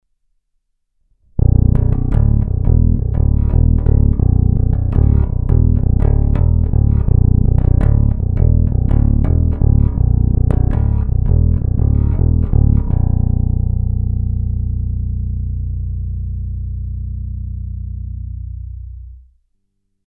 Brutálně konkrétní masívní zvuk.
Není-li uvedeno jinak, následující nahrávky jsou vyvedeny rovnou do zvukové karty a s plně otevřenou tónovou clonou, následně jsou jen normalizovány, jinak ponechány bez úprav.